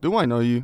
Voice Lines / Dismissive
Update Voice Overs for Amplification & Normalisation
do i know you.wav